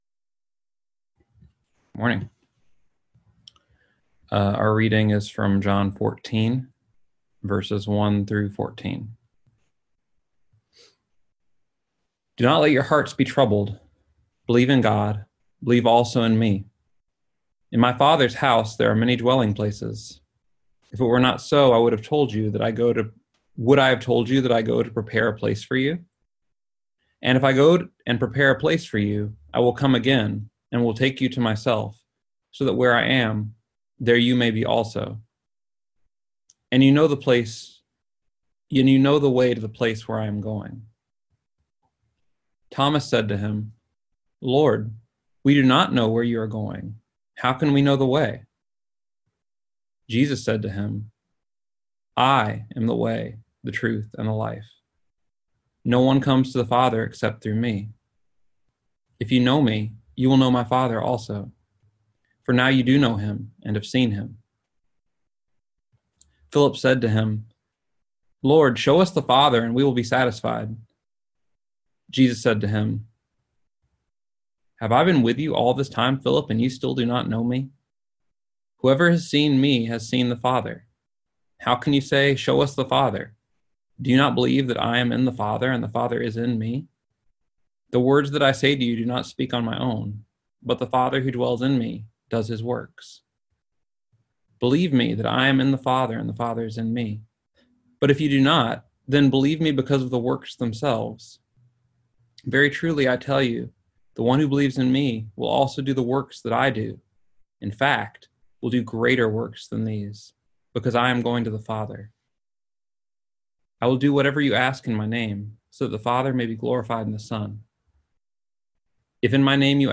Listen to the most recent message from Sunday worship at Berkeley Friends Church, “I Am The Way.”